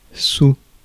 Ääntäminen
Synonyymit argent monnaie (USA) cents (USA) centin Ääntäminen France: IPA: /su/ Haettu sana löytyi näillä lähdekielillä: ranska Käännöksiä ei löytynyt valitulle kohdekielelle.